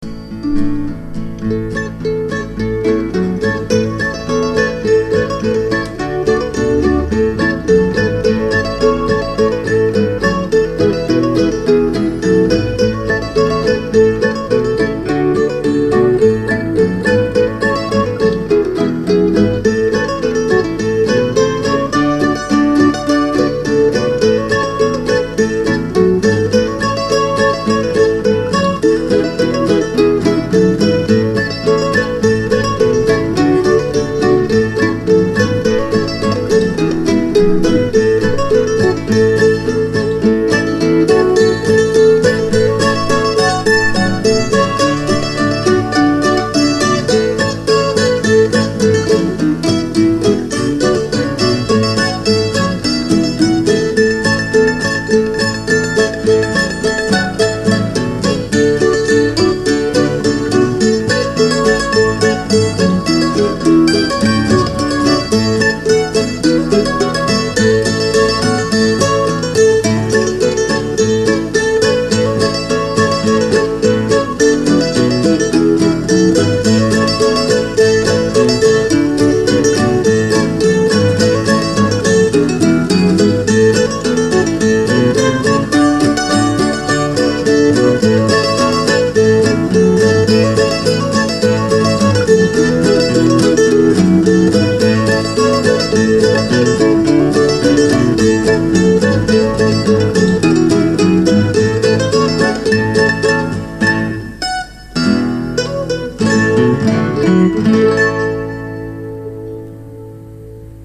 Instrumentals
Trad., arr. Room Temperature.